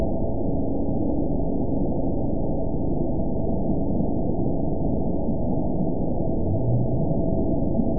event 922870 date 04/29/25 time 15:28:14 GMT (7 months ago) score 8.81 location TSS-AB02 detected by nrw target species NRW annotations +NRW Spectrogram: Frequency (kHz) vs. Time (s) audio not available .wav